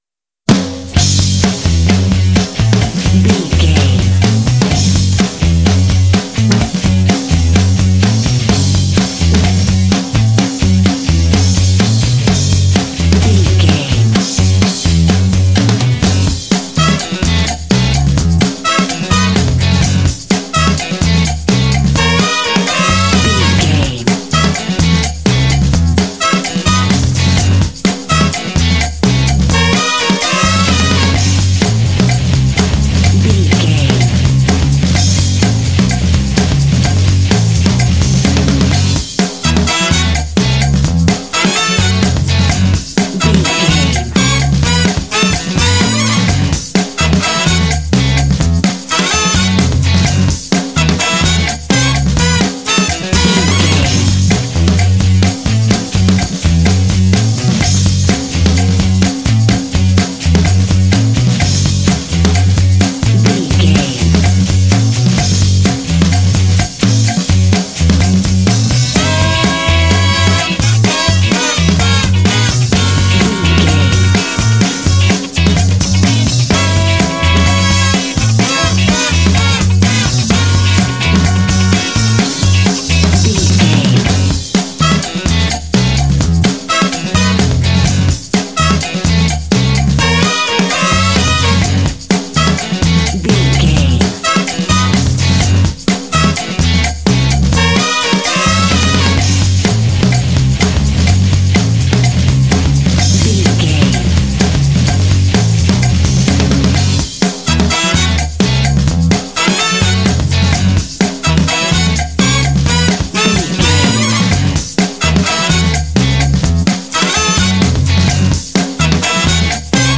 Uplifting
Aeolian/Minor
F#
powerful
energetic
groovy
horns
brass
drums
electric guitar
bass guitar